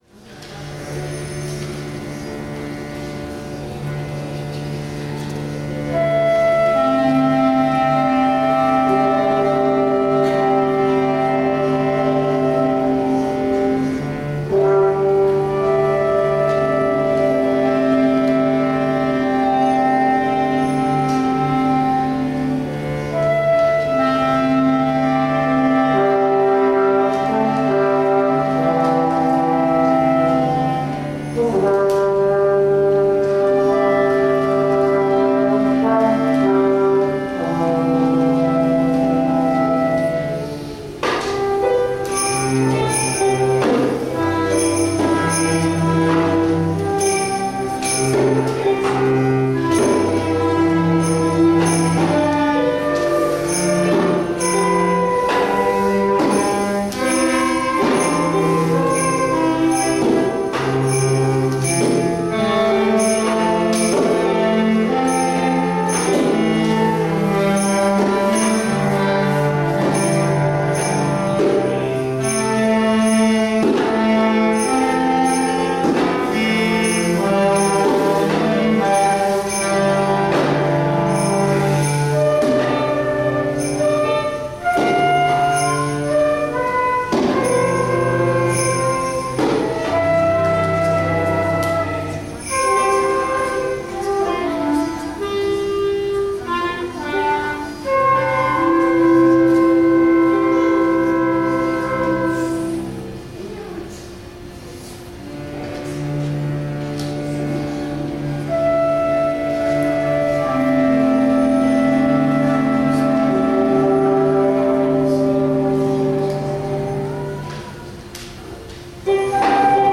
Banda de música de manzanares el real
Para celebrar la Navidad ralizamos nuestro concierto en la sala de plenos del Ayuntamiento,.